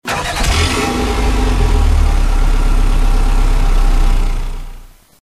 Home gmod sound vehicles tdmcars mr2gt
enginestart.mp3